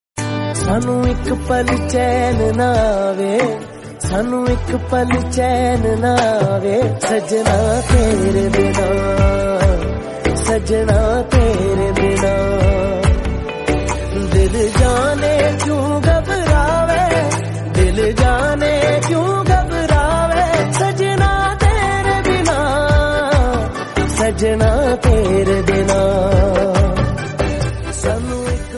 soothing melody